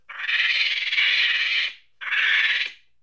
c_mnky_atk1.wav